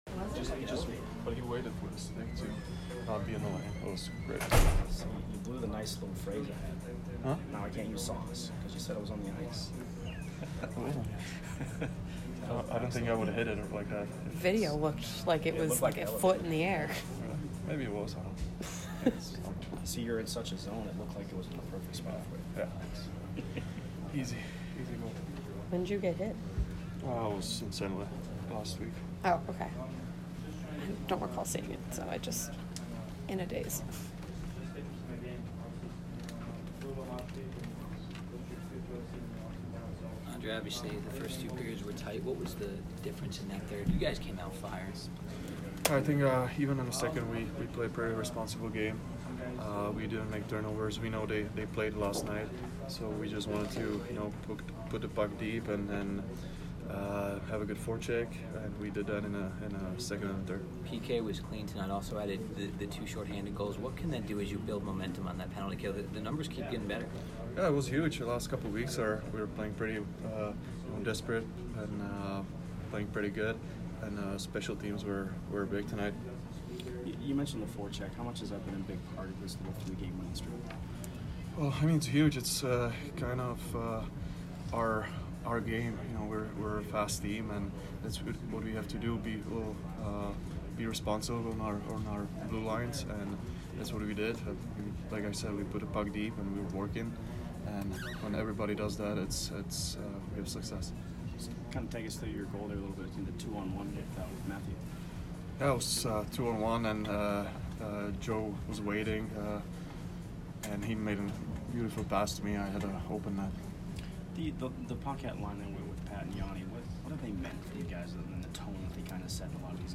Palat post-game 11/25